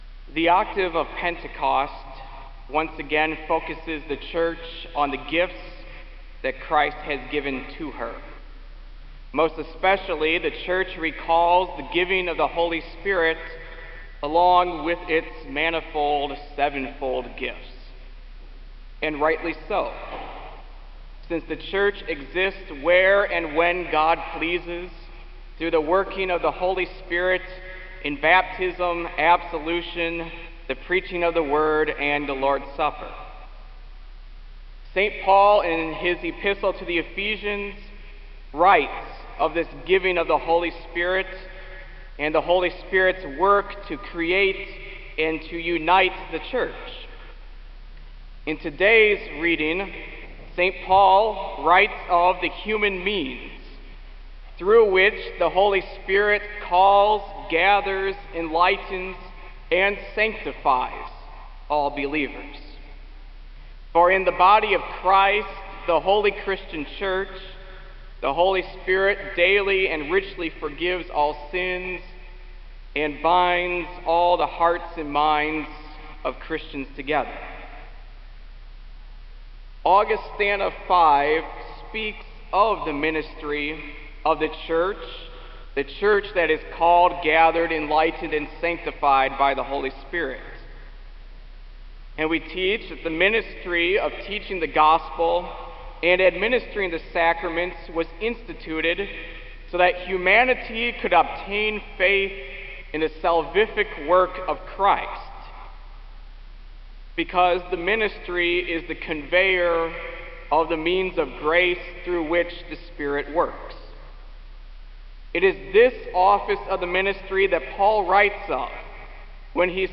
Kramer Chapel Sermon - June 04, 2004